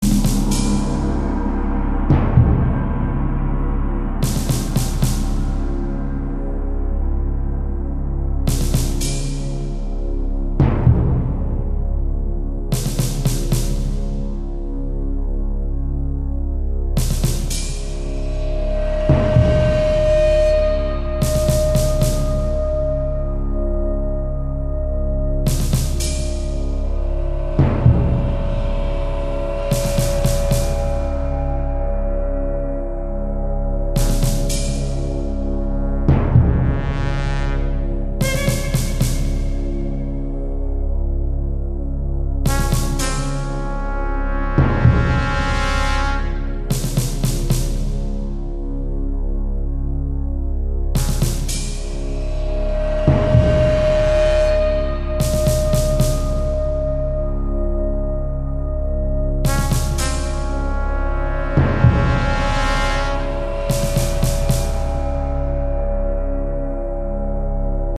Millitary Ambient[Mp3]
Millitary music.
Sounds great, but I'm not sure about the trumpet sounds near the end.
millitary_loop_268.mp3